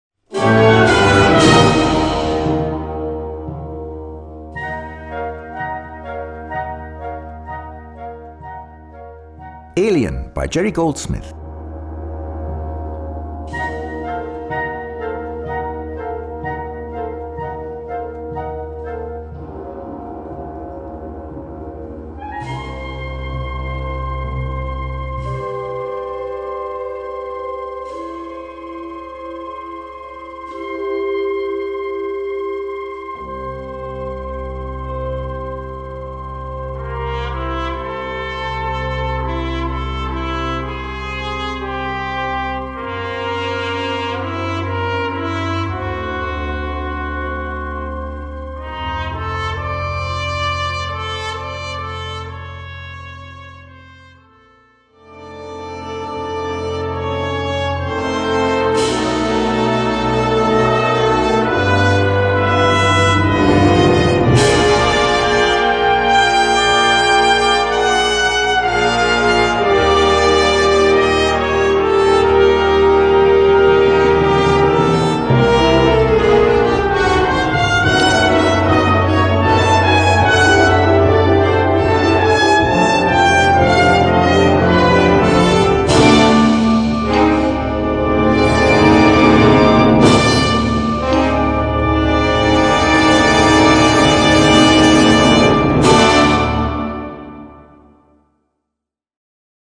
Gattung: Filmmusik
Blasorchester